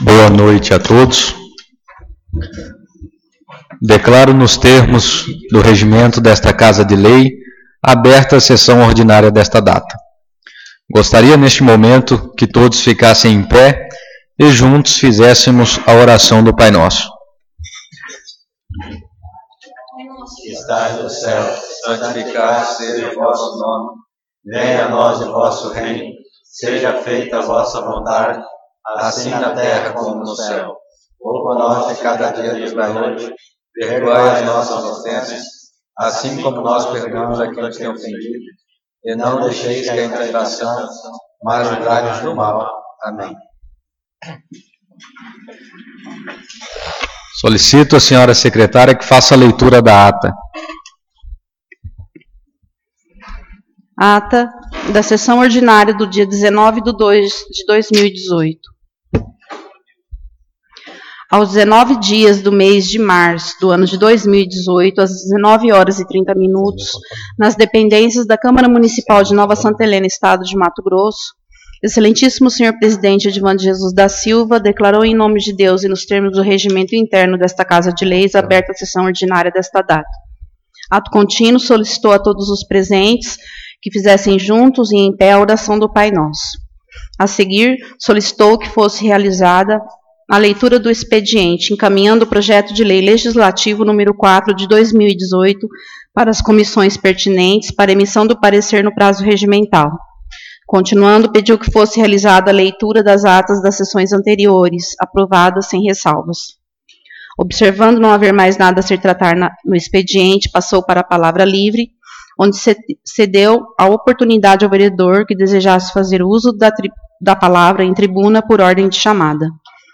Sessão Ordinária 19/03/2018